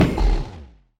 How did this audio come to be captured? should be correct audio levels.